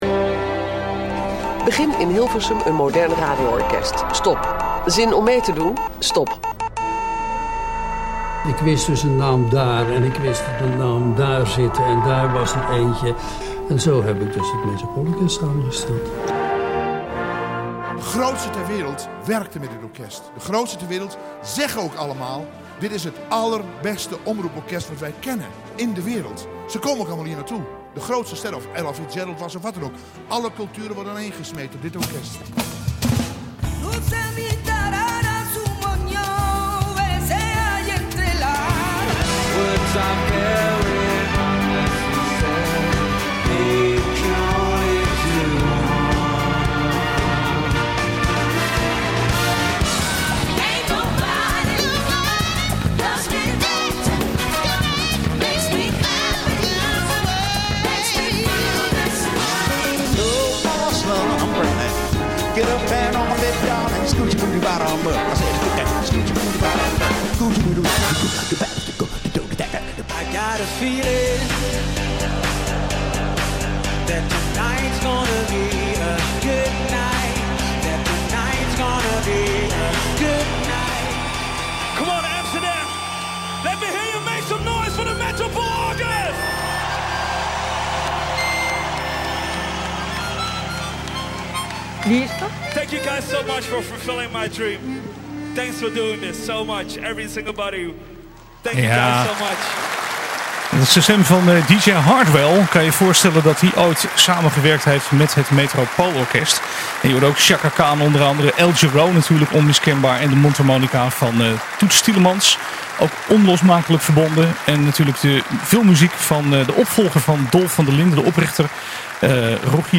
praat hier gepassioneerd over.